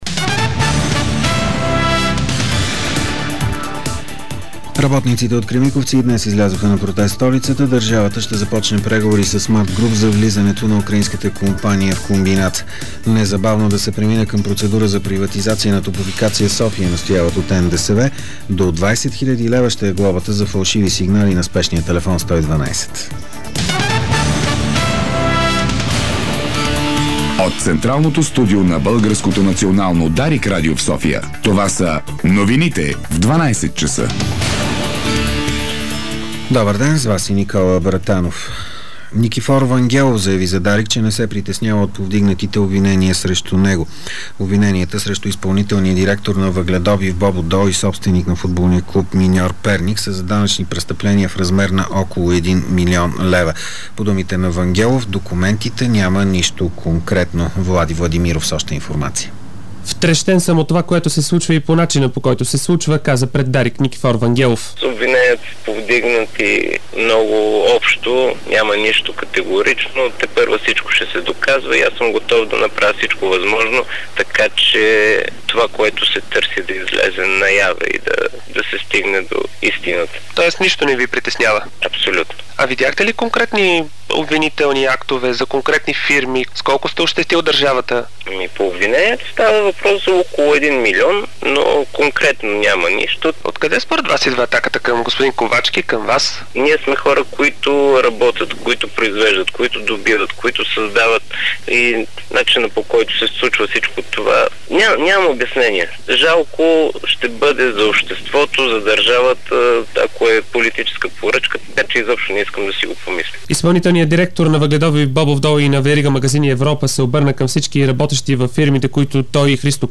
Обедна информационна емисия - 14.11.2008